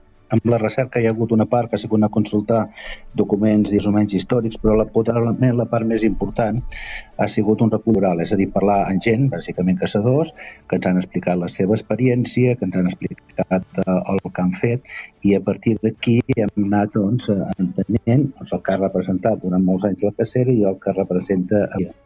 Entrevistes Supermatí